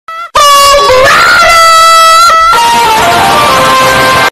Clash Royale Hog Rider Sound Effect Free Download